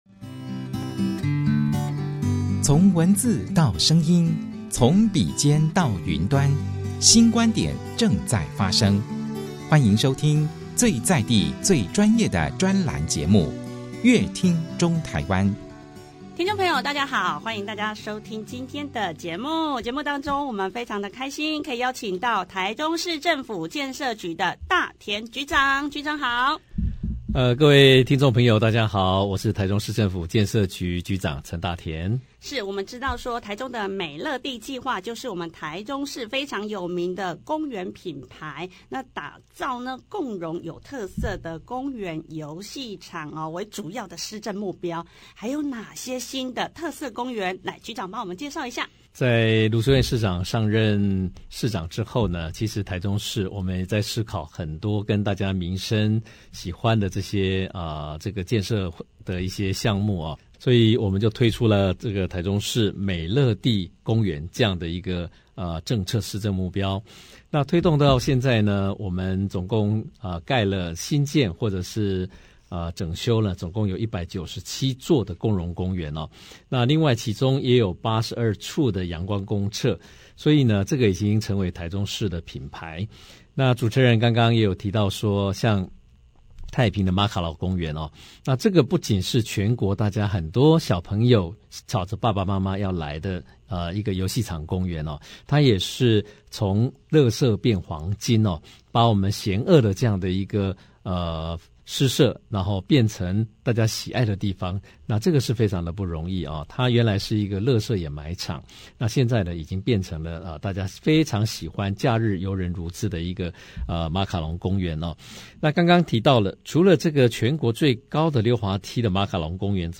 打造特色兒童遊戲場 陳局長在節目中非常詳盡為聽眾介紹，臺中美樂地計畫是臺中市享譽盛名的公園品牌，以打造共融有特色的公園遊戲場為主要的施政目標，並補充跟大家分享，臺中市還有許多特色公園以及許多有趣好玩的遊具設施，節目最後，陳局長也在節目中跟市民朋友介紹，臺中市民野餐日，就在今年11月26日溫馨舉辦，陳局長期望並藉由這個活動傳遞共榮友好的美樂地精神，也期待能延續去年首屆野餐日的大成功，讓更多民眾感受到幸福感！